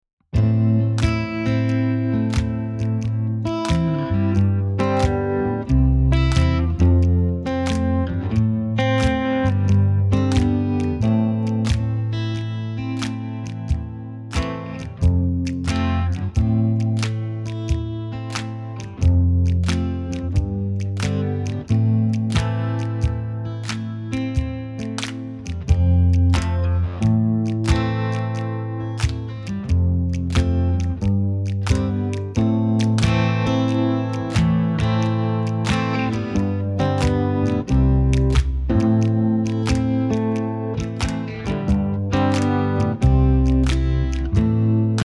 Listen to a sample of the sing-along track.
2. Mp3 Instrumental Sing Along track;